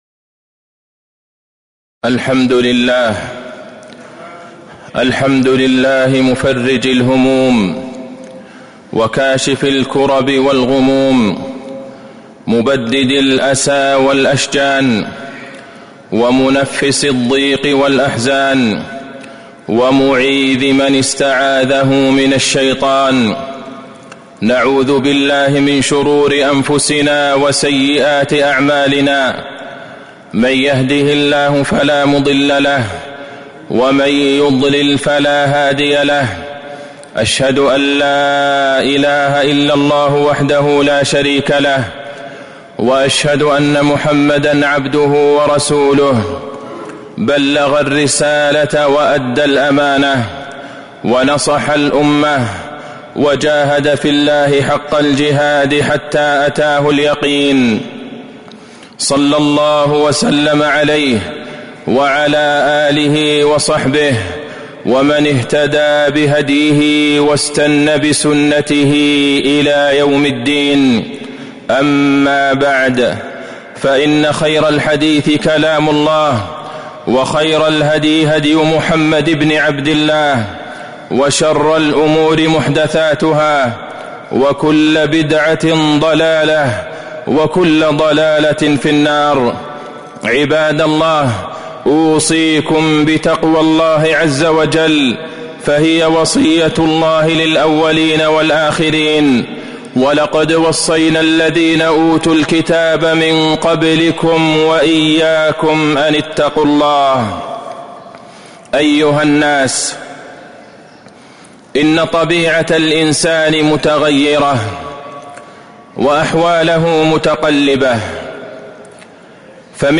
تاريخ النشر ١٢ جمادى الآخرة ١٤٤٦ هـ المكان: المسجد النبوي الشيخ: فضيلة الشيخ د. عبدالله بن عبدالرحمن البعيجان فضيلة الشيخ د. عبدالله بن عبدالرحمن البعيجان من أسباب إنشراح الصدر